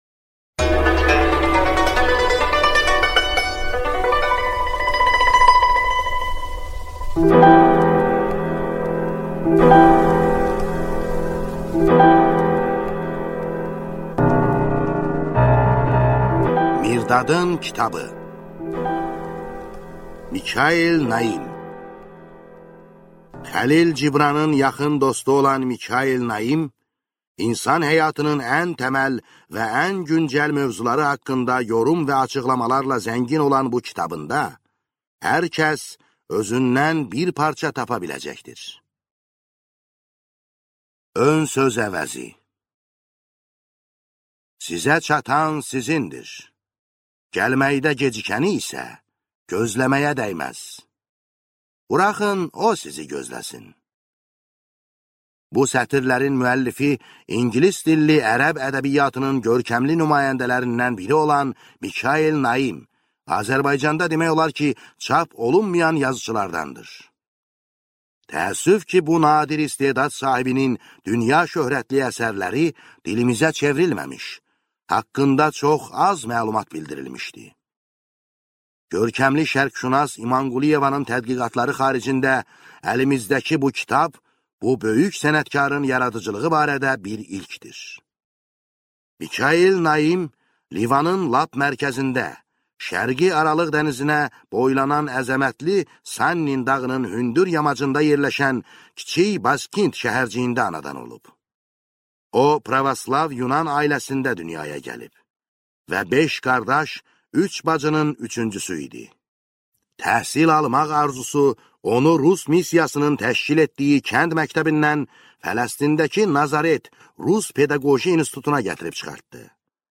Аудиокнига Mirdadın kitabı | Библиотека аудиокниг